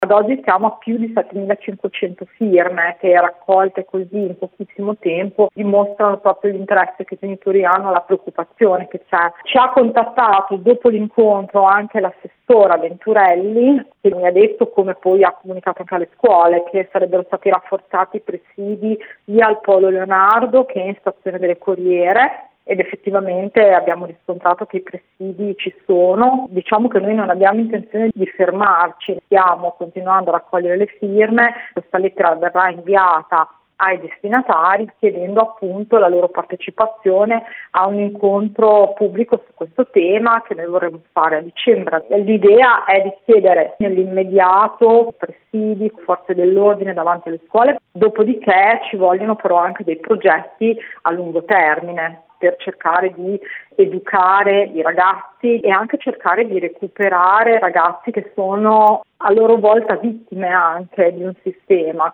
intervistata